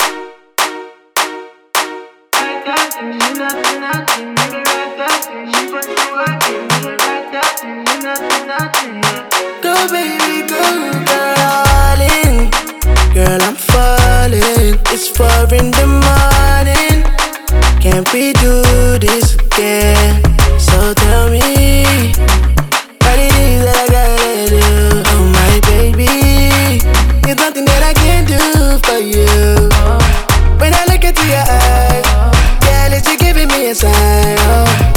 Скачать припев
Afrobeats